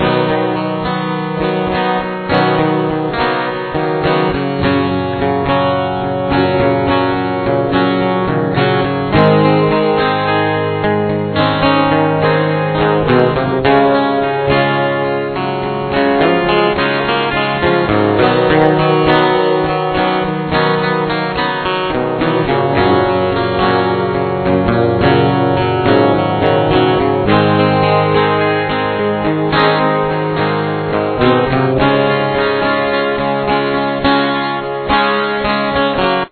Verse 1